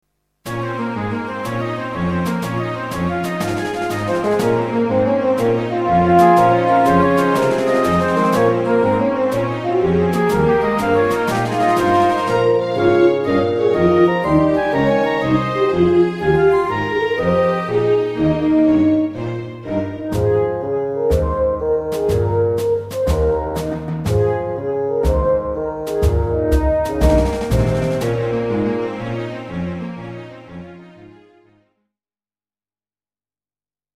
klassiek
In afwachting van de start van een autorace, spannend.